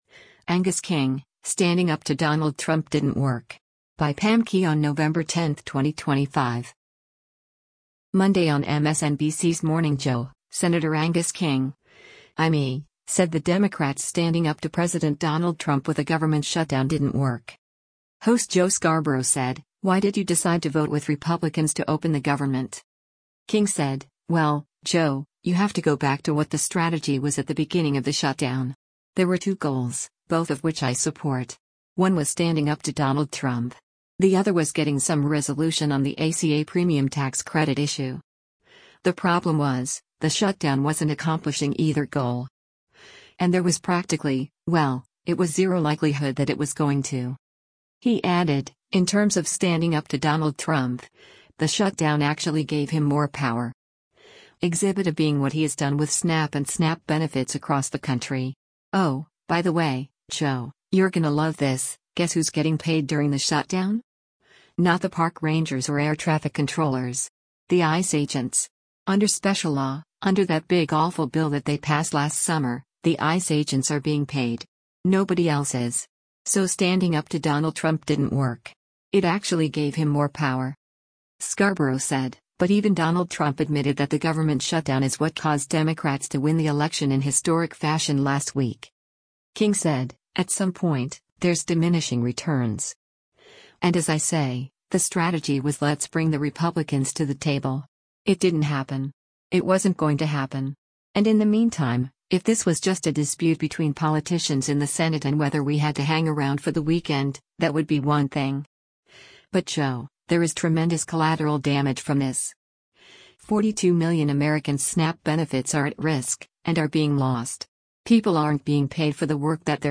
Host Joe Scarborough said, “Why did you decide to vote with Republicans to open the government?”